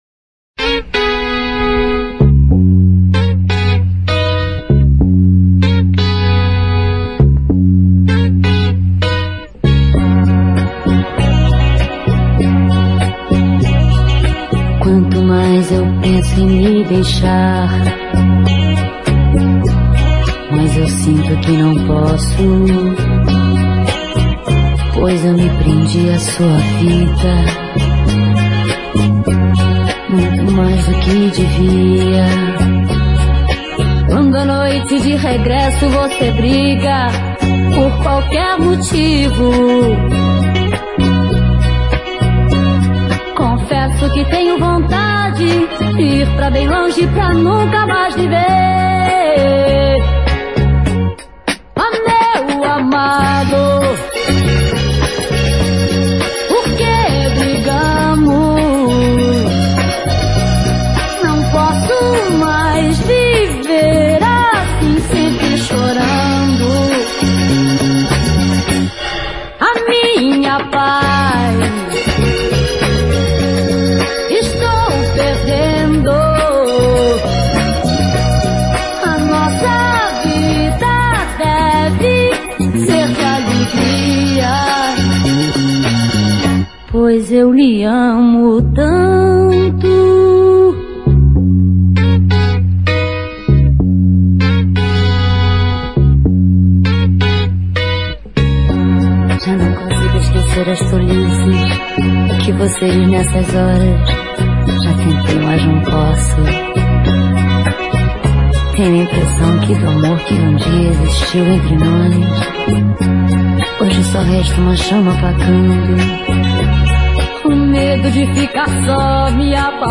Bregas